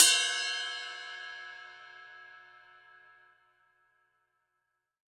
Ride Cymbal Sample G Key 01.wav
Royality free ride cymbal single hit tuned to the G note. Loudest frequency: 5281Hz
ride-cymbal-sample-g-key-01-v20.ogg